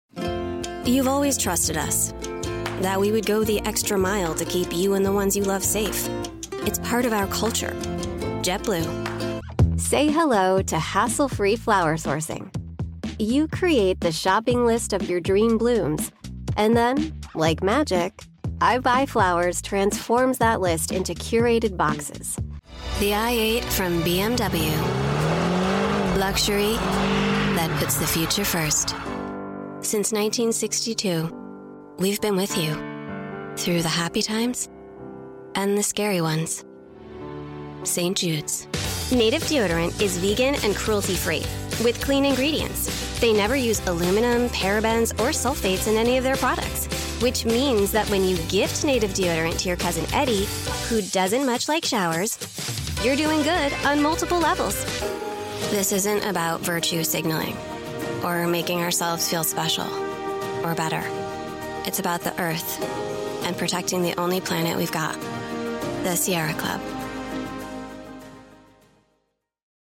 Voice Artists - Cool